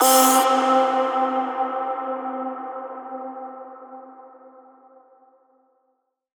VR_vox_hit_oooh_C.wav